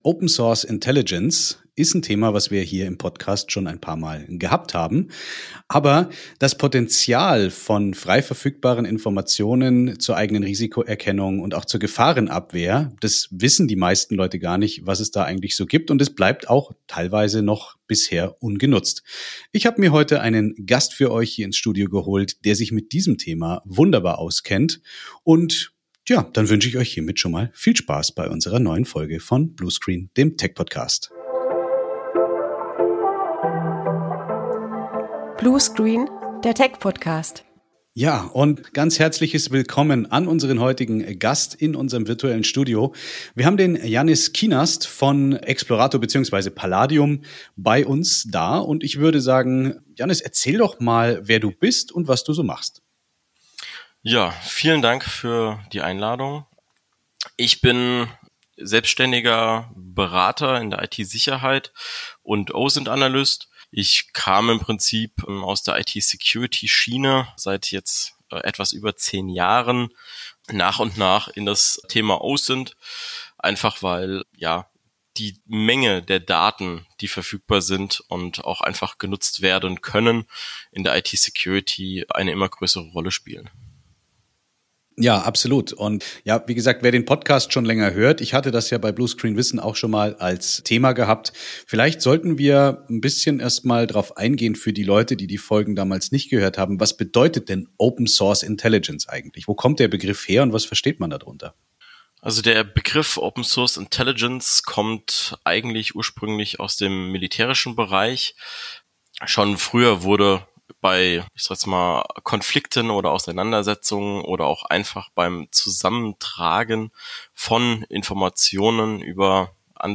086: OSINT - Die Macht freier Informationen. Ein Interview